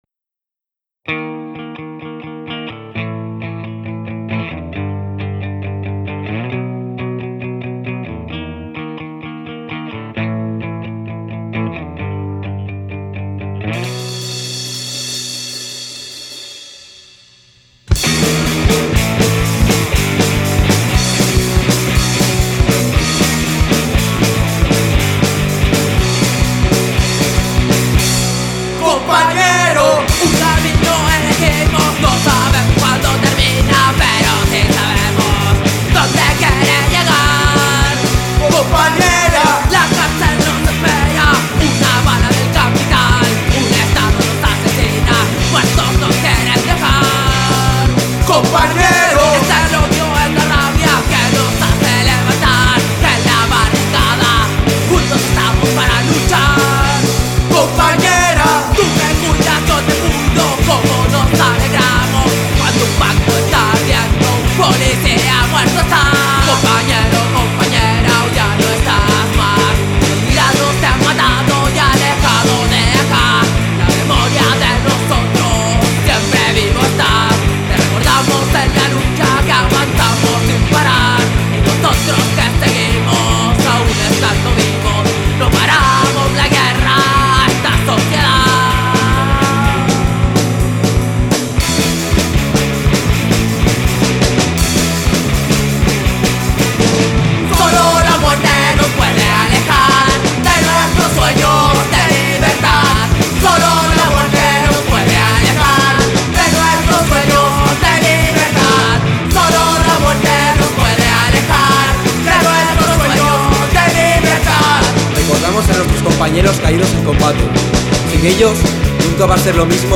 voz
guitarra
batería
bajo